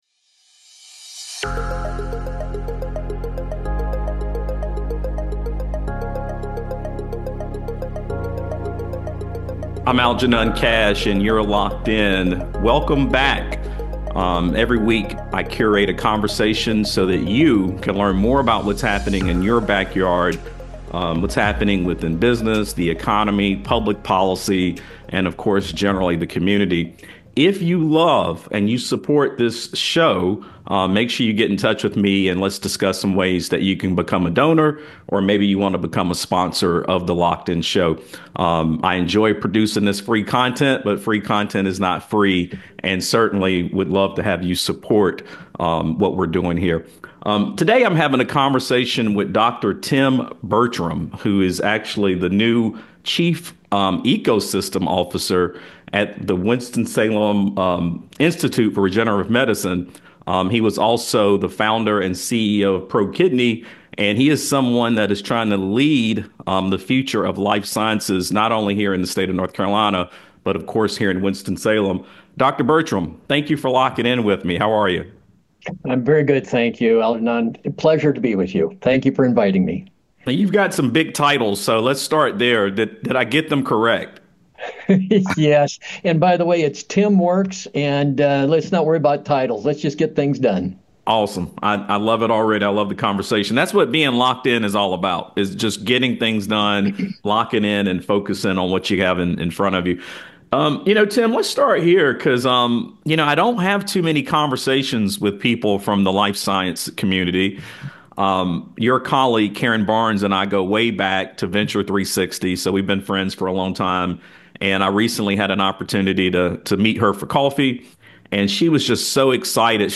The Future of Life Sciences in Winston-Salem: Interview